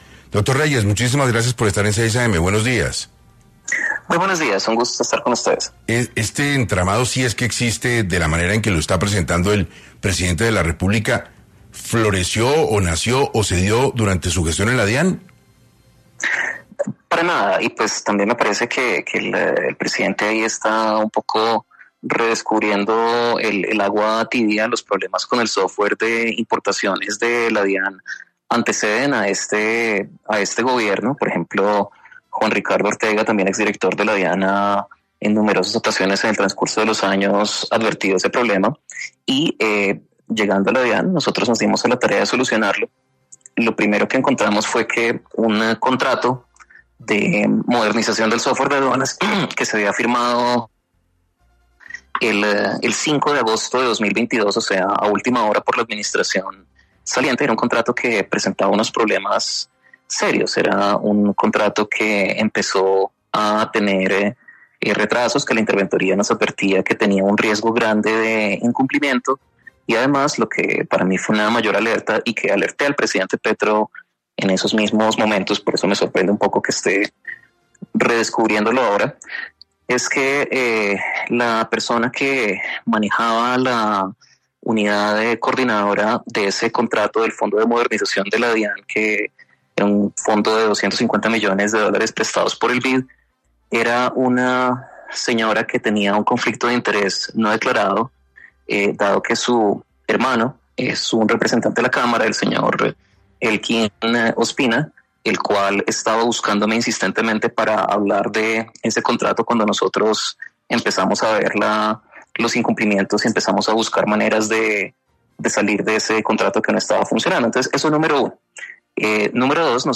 El exdirector de la DIAN y también precandidato presidencial, Luis Carlos Reyes, explicó en 6AM cómo funciona este software que denunció el presidente, pero que él previamente ya había denunciado.